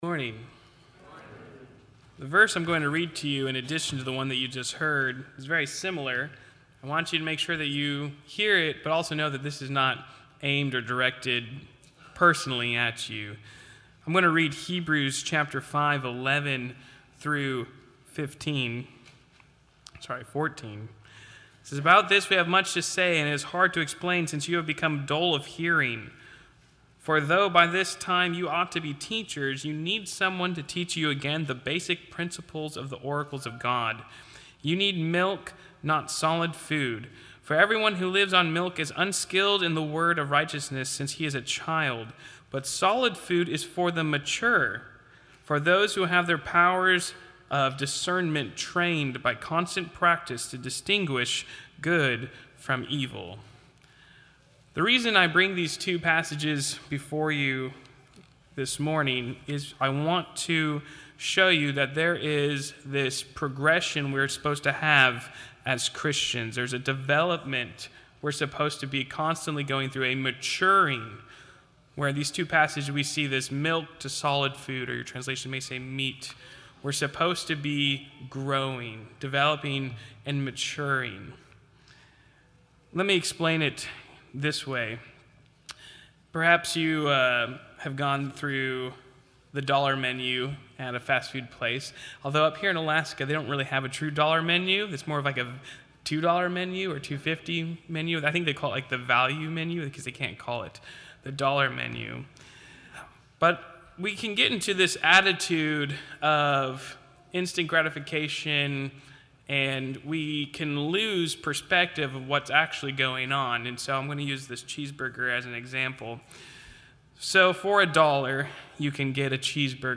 Audio Sermons From Milk to Meat